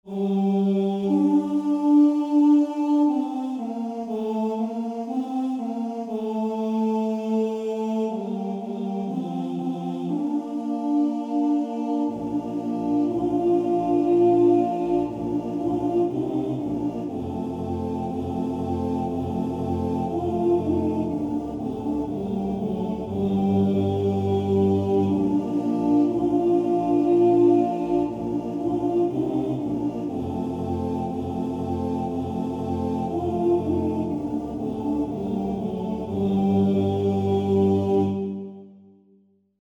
Комп'ютерне відтворення нот (mp3):